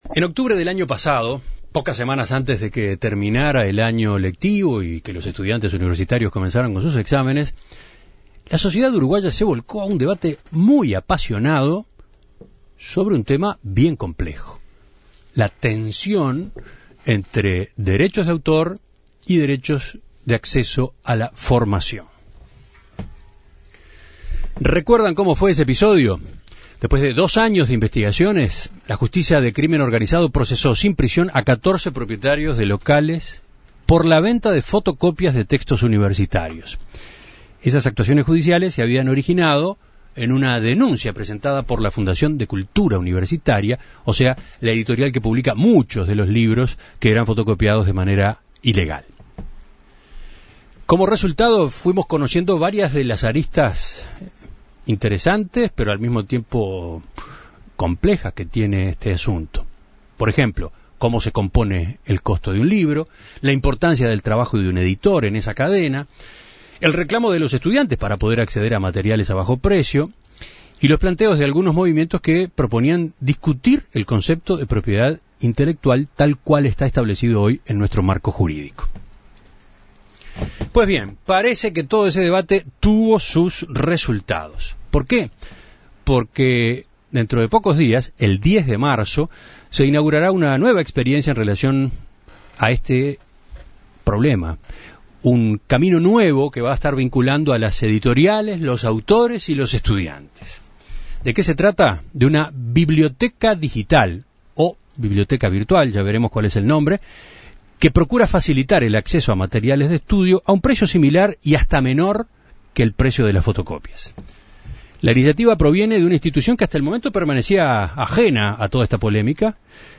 Previos al comienzo de clase, la Dirección Nacional de Impresiones y Publicaciones Oficiales (IMPO) creó una biblioteca virtual. Gonzalo Reboledo, director del IMPO, contó a En Perspectiva que, en un principio, la iniciativa está destinada solamente a los estudiantes de la Facultad de Derecho; sin embargo, el proyecto a largo plazo es abarcar a todas las carreras universitarias.